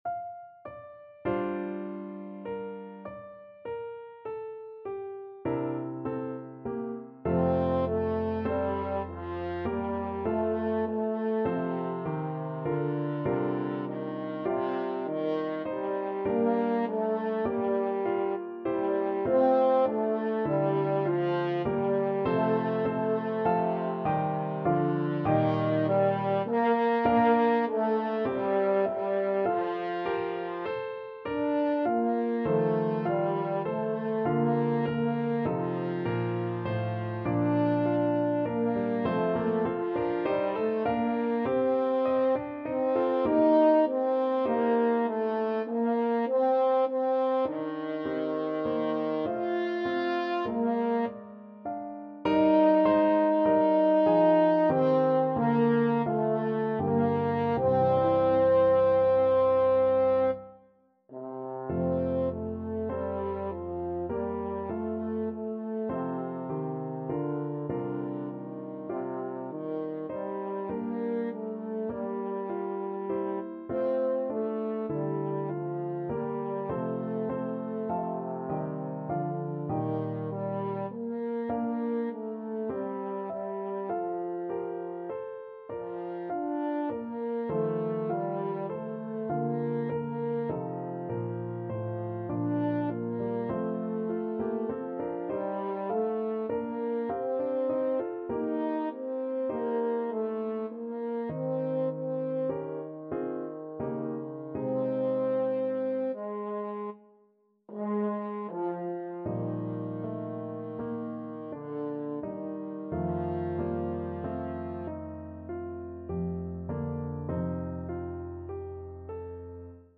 French Horn version
5/4 (View more 5/4 Music)
Classical (View more Classical French Horn Music)